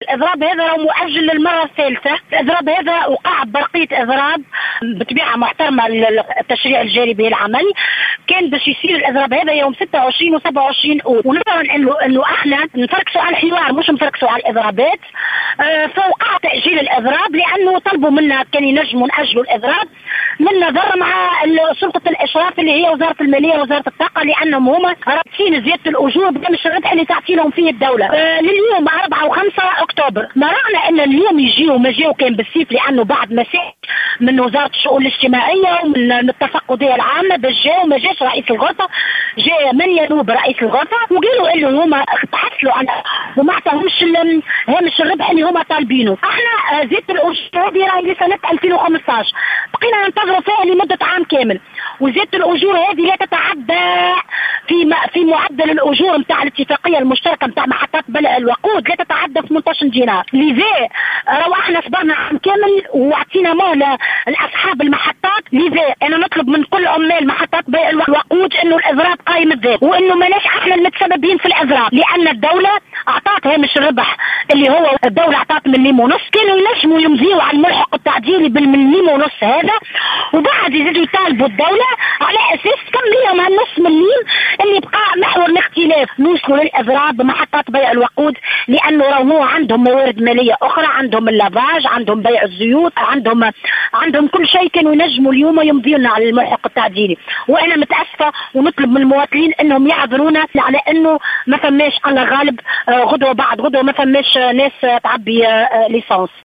تصريح لـ "الجوهرة اف أم"